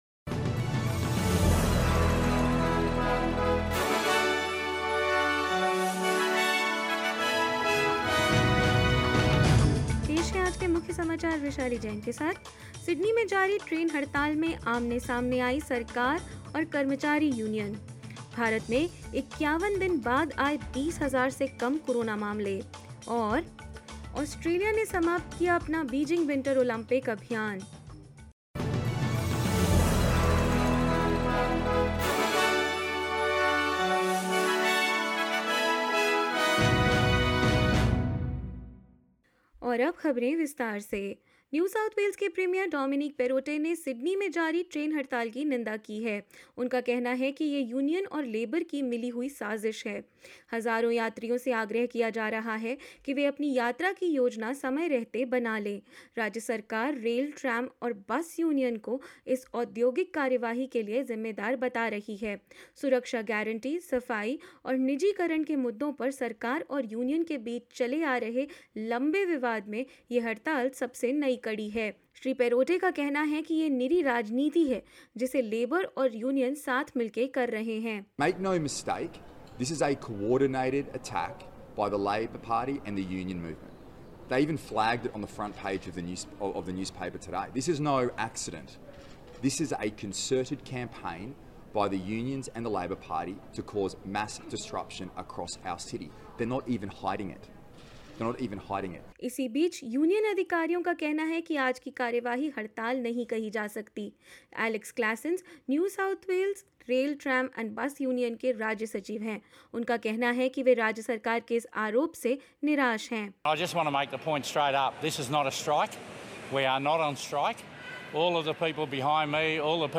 In this latest SBS Hindi bulletin: A city-wide train strike in Sydney has government and union officials at odds; India registers less than 20 thousand COVID-19 cases after 51 days; Australia wraps up its Olympic campaign at the Beijing winter games and more news.